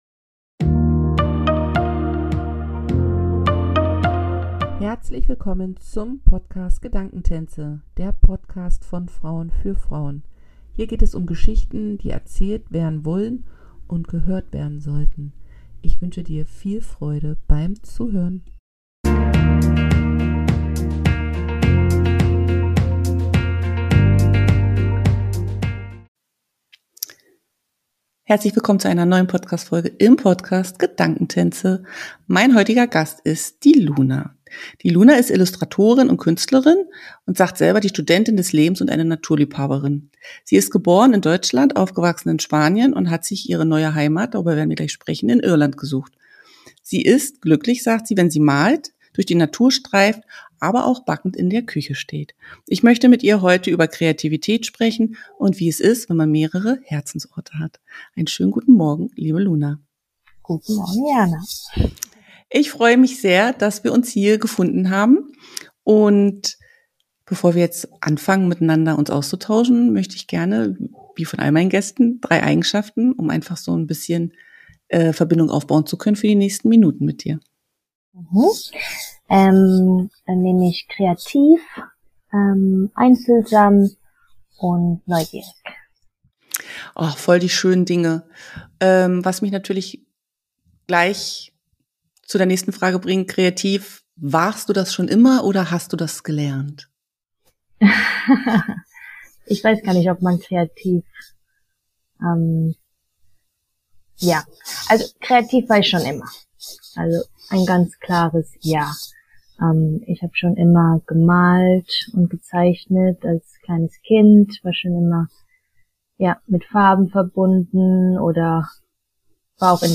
Wir sprechen zudem über das Gefühl von zu Hause zu sein und das es weniger eine physische Ortschaft als vielmehr ein innerer Ort ist. Es ist ein zartes, inspirierendes Gespräch entstanden, dass deutlich macht, dass Kreativität uns stets begleitet und uns in unserem Sein unterstützt.